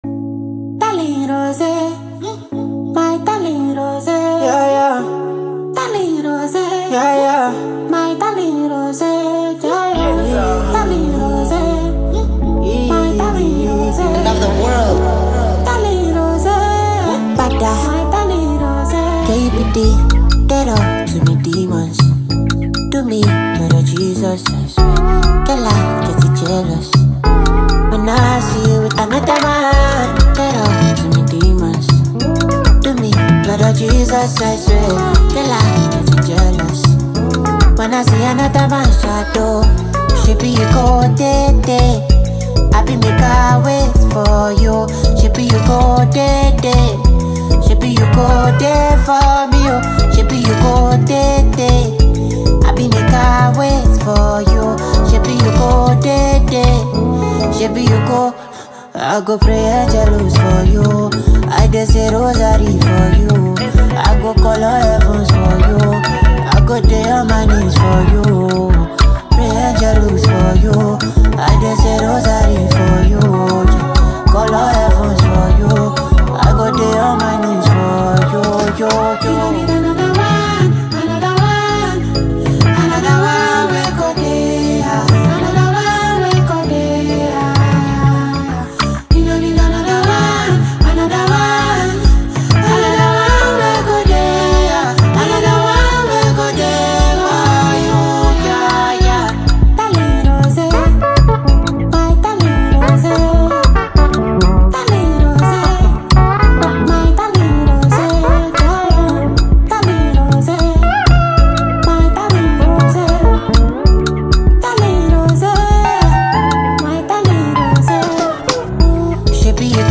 upbeat afrobeat song laced with some old skool instrumentals